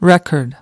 record-noun.mp3